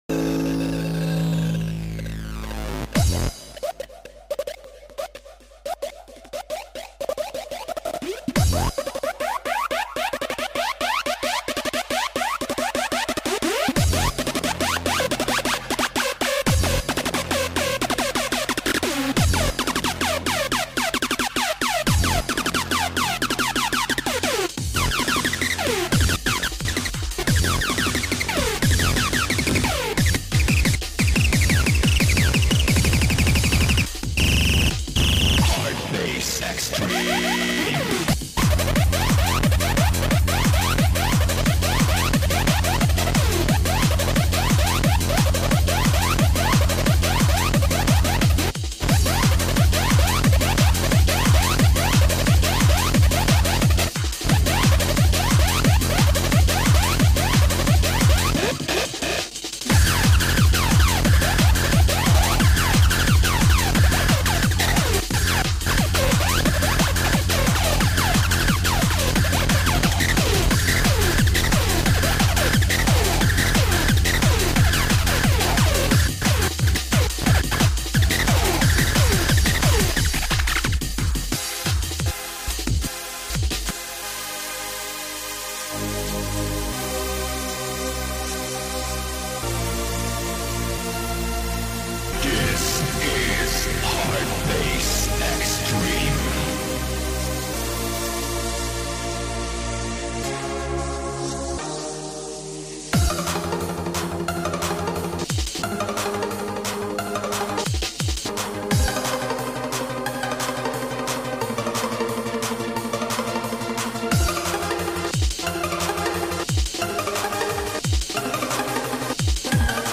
Oldskool Vocals Hardcore